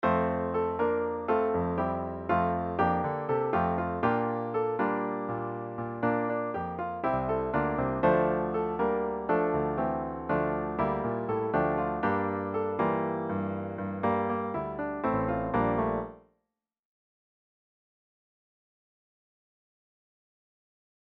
3) Example 3 is the 8-bar melody, with the first 4 bars in F major, and the next 4 bars featuring the melody still in F major, but with harmonies from D minor. This probably provides the smoothest transition of all possibilities, since the melody stays exactly the same.